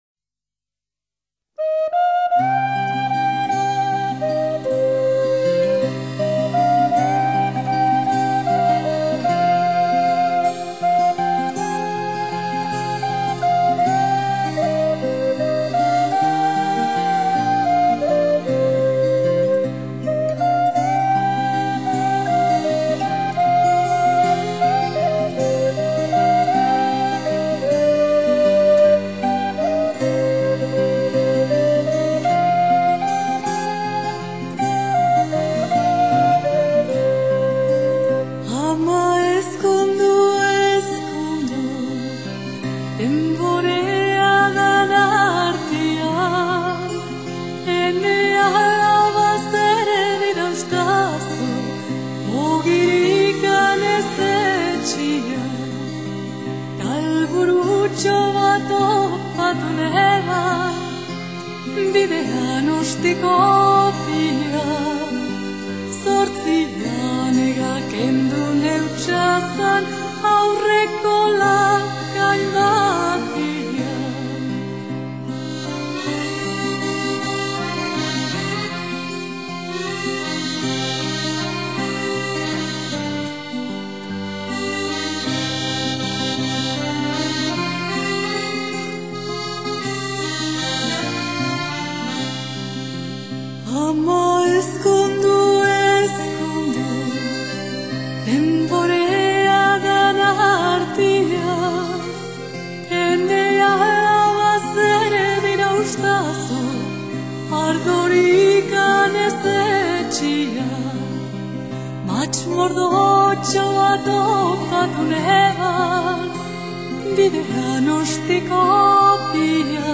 [29/9/2009]西班牙民谣 激动社区，陪你一起慢慢变老！